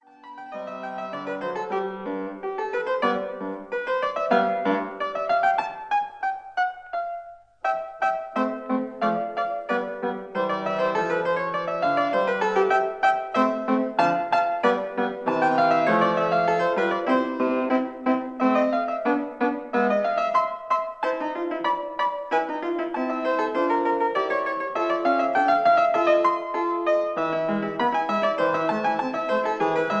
fortepiano
Rondo